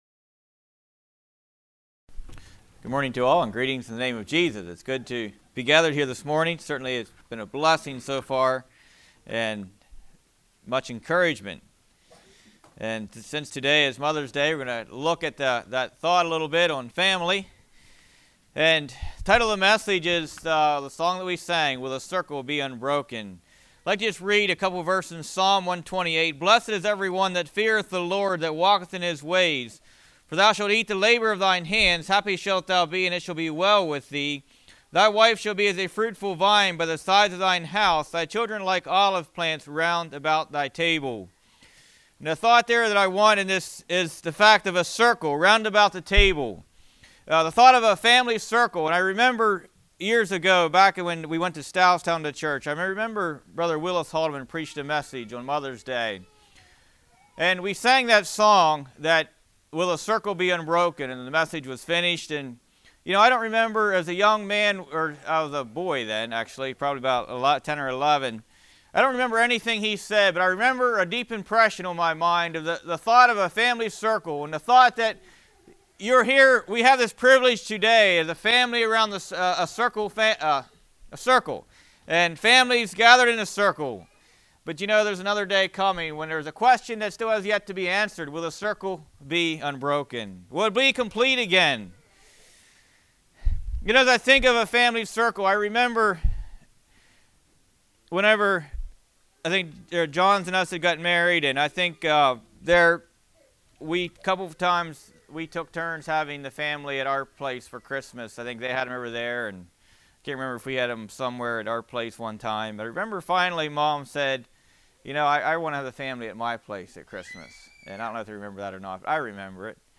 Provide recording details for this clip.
Congregation: Winchester